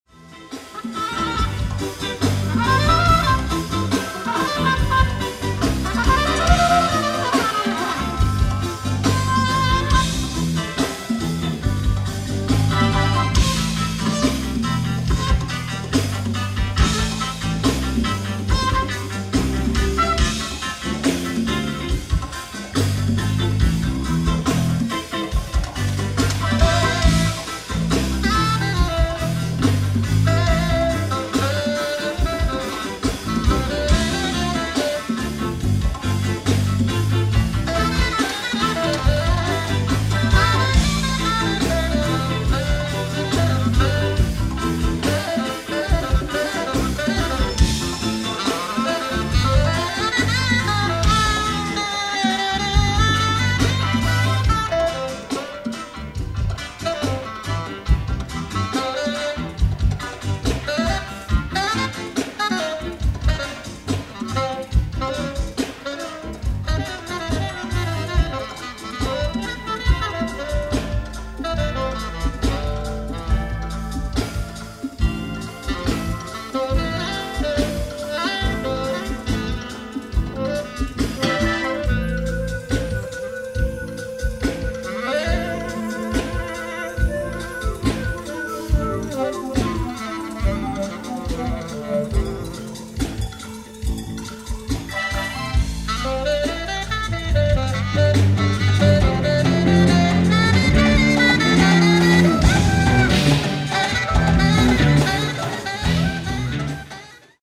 ライブ・アット・ピア８４、ニューヨーク・シティー、ニューヨーク 08/17/1985
※試聴用に実際より音質を落としています。